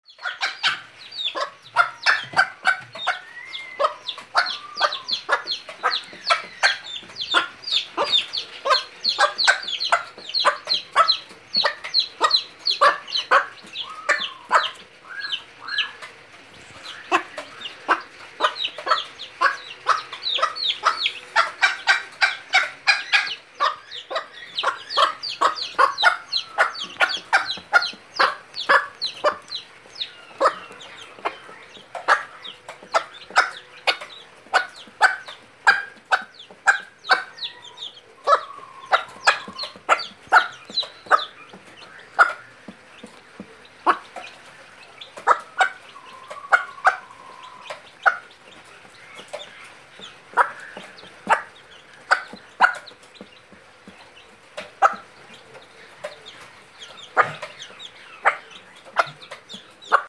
Tiếng Gà Mái Mẹ gọi con mp3